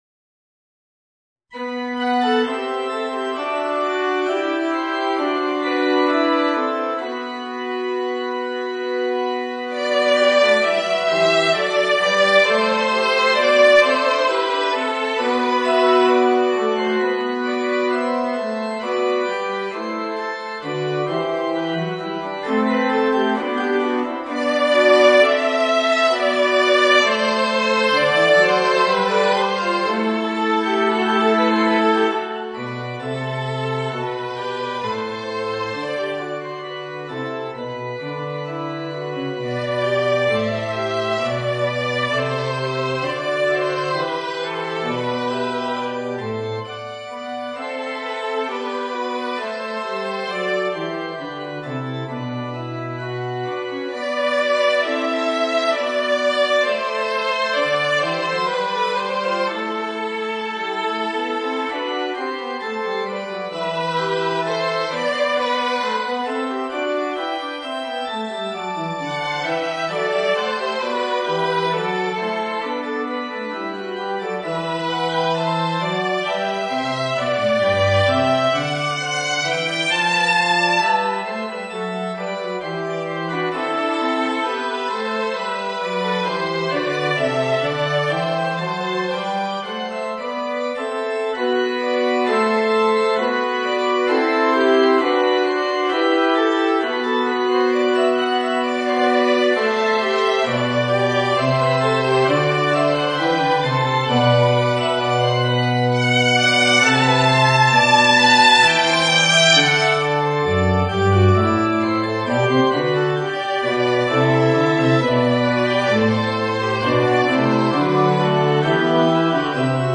Voicing: Violin and Organ